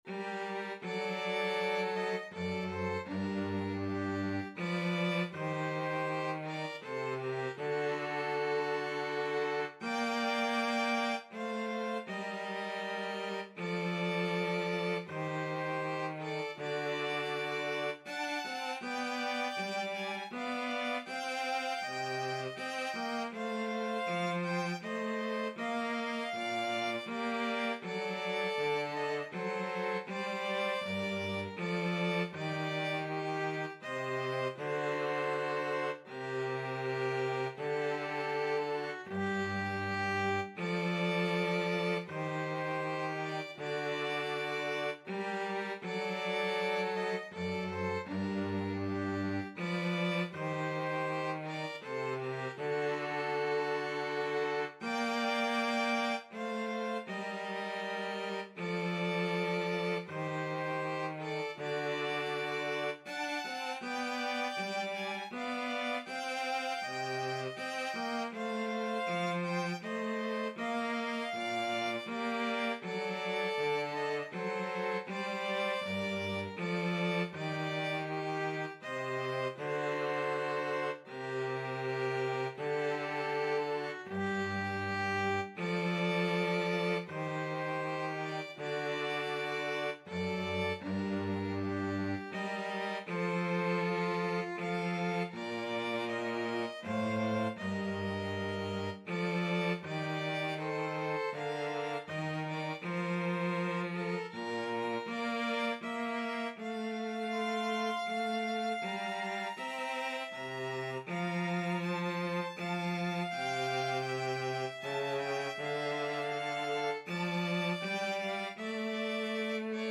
Classical (View more Classical 2-Violins-Cello Music)